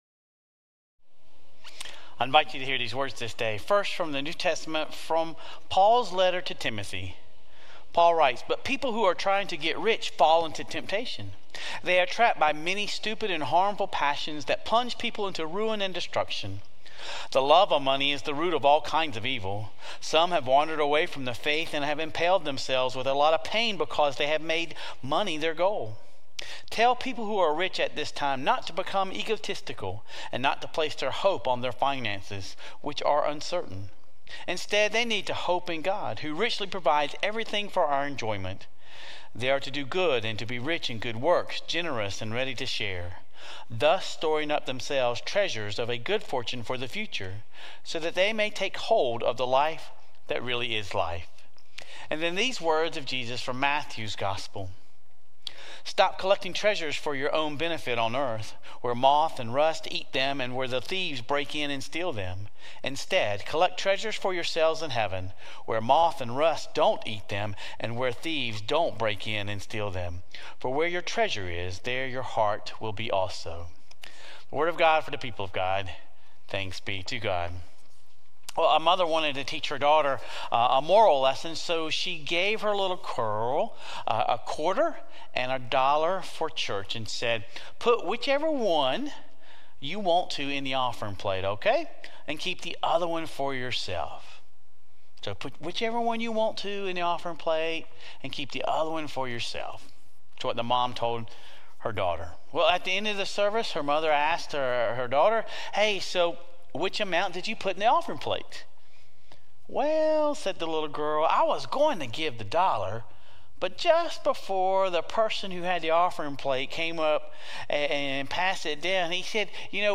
Sermon Reflections: Have you ever witnessed or experienced the negative consequences of prioritizing money over other values?
Sunday morning traditional livestream